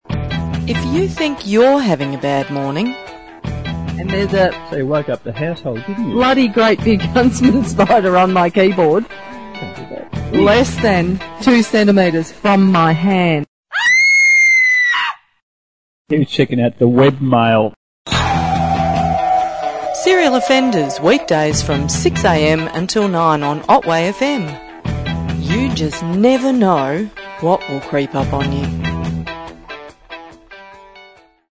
I created this promo from Friday morning's program.